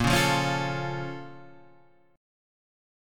A# Minor Major 7th